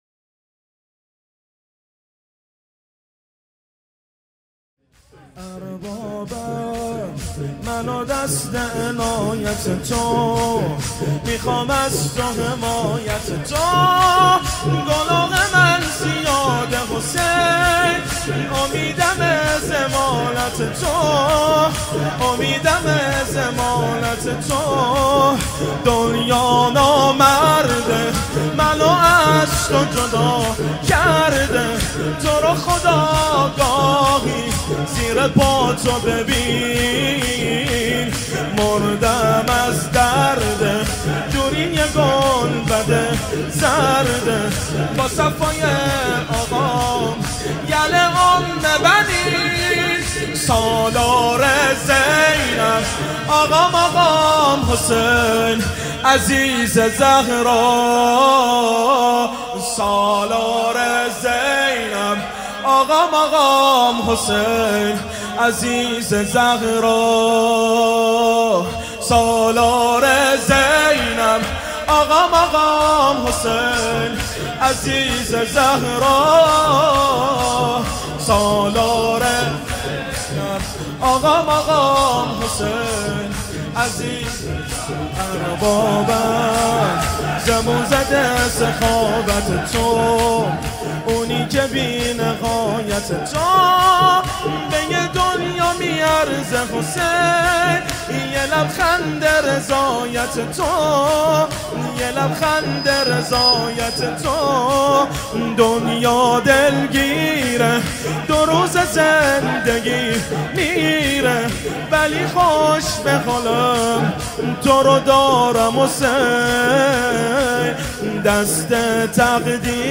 شور | اربابم، من و دستِ عنایت تو
مداحی
شب 5 محرم 1439هجری قمری | هیأت علی اکبر بحرین